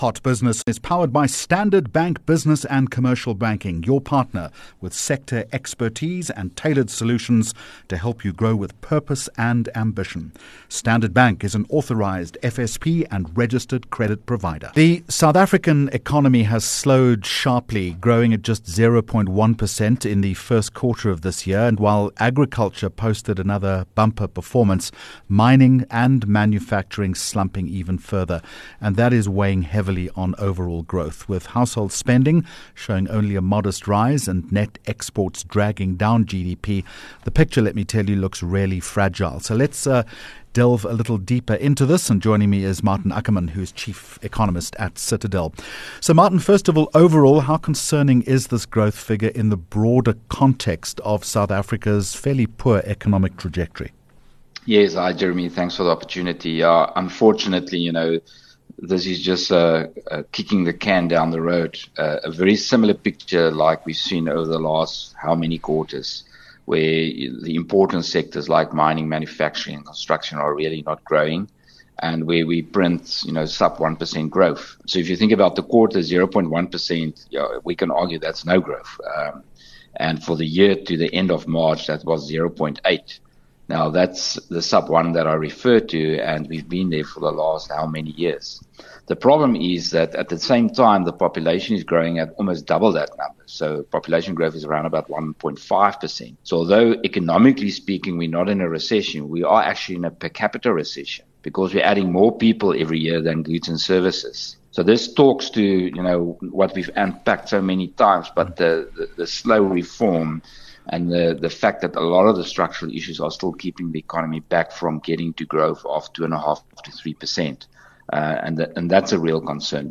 3 Jun Hot Business Interview